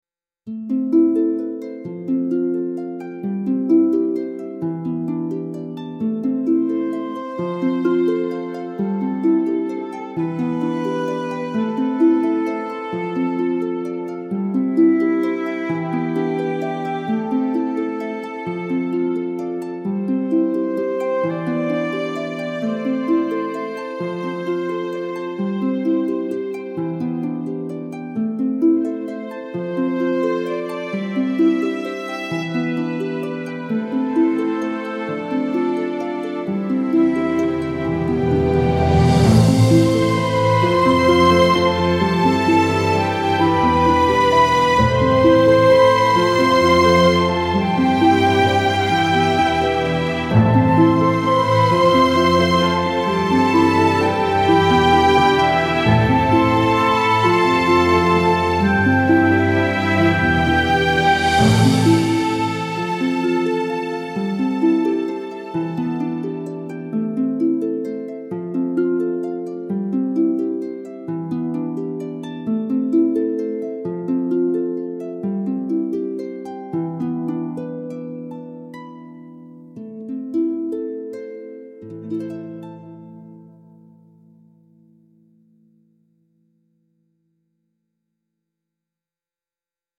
elegant waltz with lush strings and gentle harp arpeggios